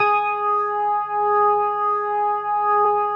B3LESLIE G#5.wav